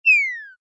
ENC_Lose_head_down.ogg